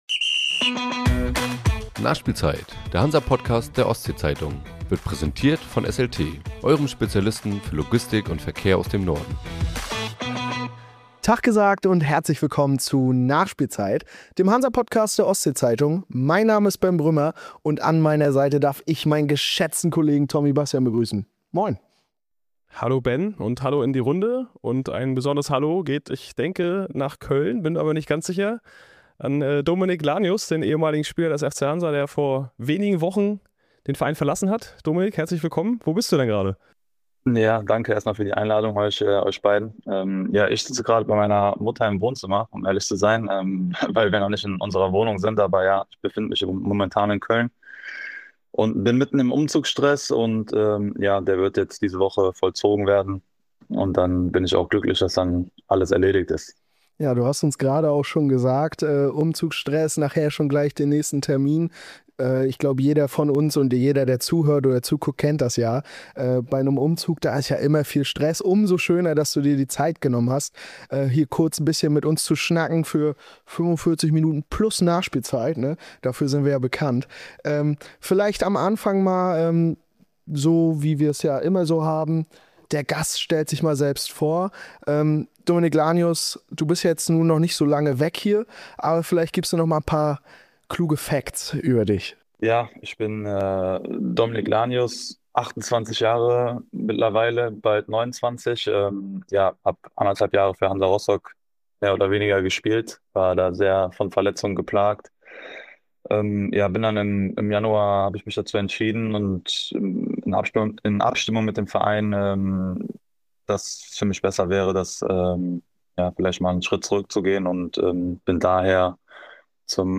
im Gespräch mit den Hosts